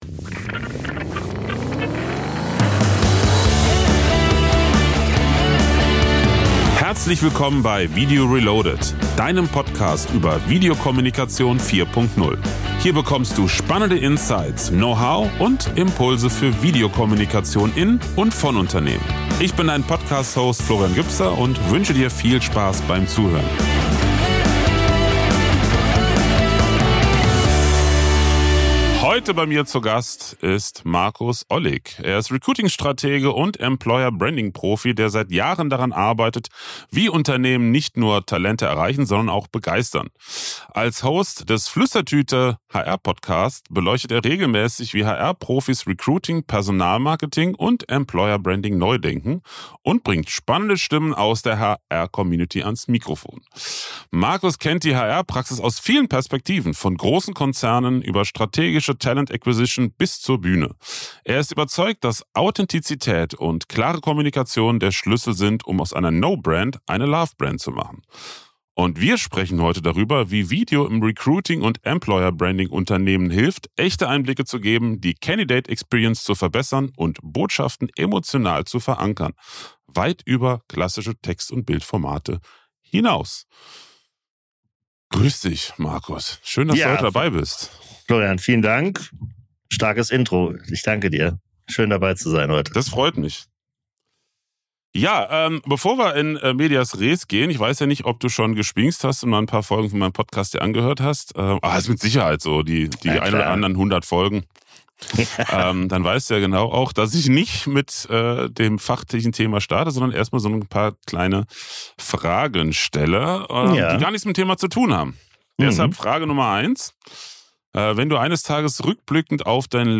Wir sprechen offen darüber, welche Fehler Unternehmen im Recruiting immer noch machen und weshalb ein ehrlicher Blick auf die eigene Zielgruppe oft der Gamechanger ist.